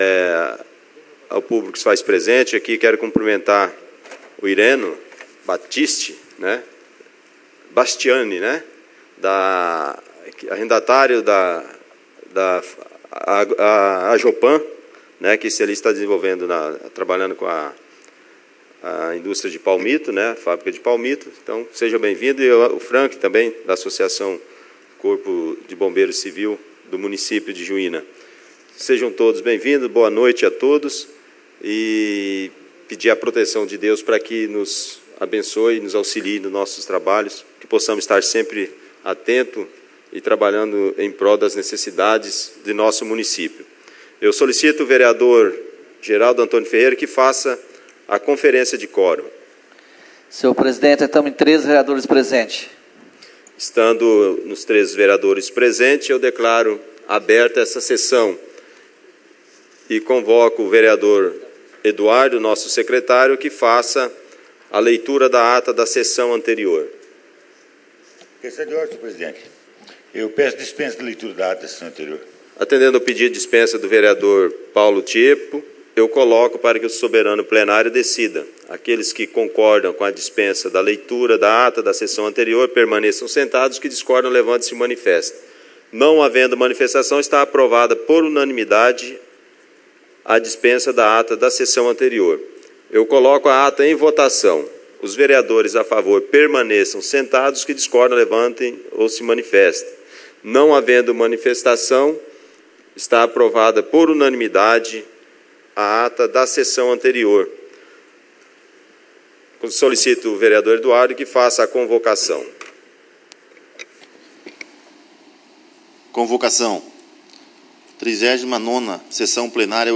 13/11/2017 - Sessão Ordinária